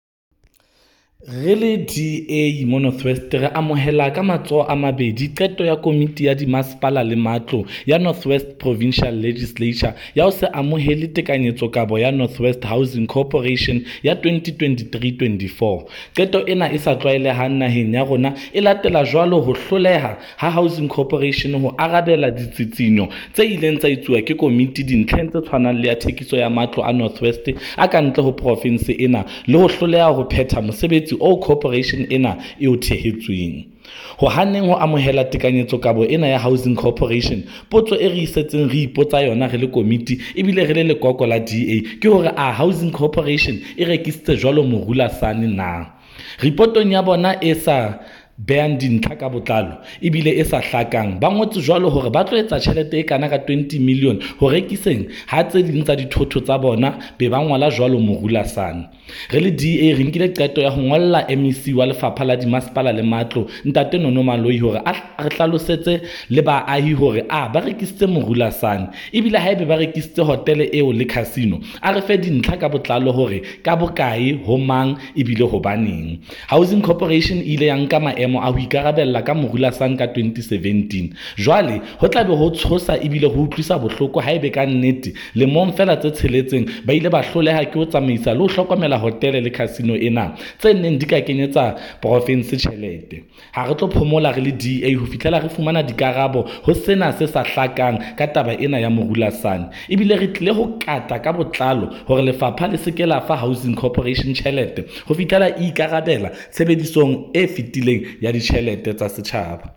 Note to Broadcasters: Please find attached soundbites in
Freddy-Sonakile-MPL-Sesotho-NW-Government.mp3